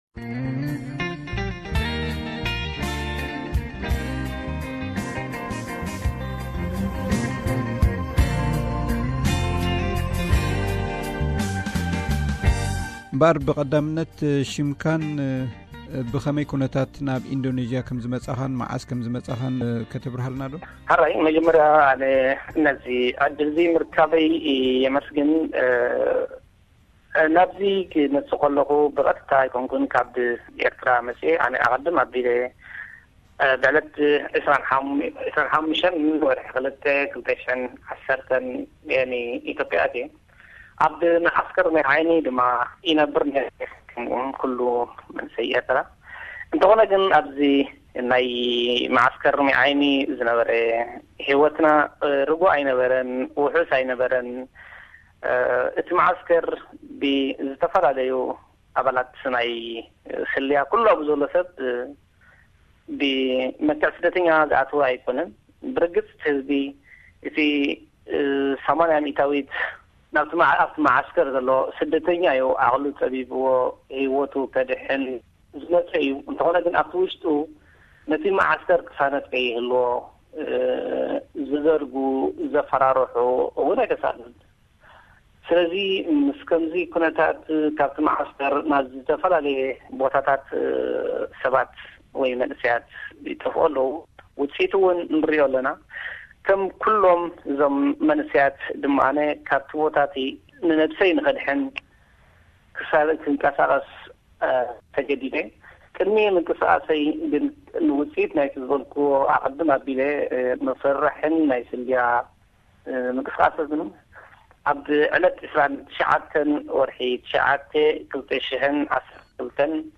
An Interview with Eritrean Asylum Seeker in Indonesia Part 1
This person is one of them and he speaks on the journey he has already been through....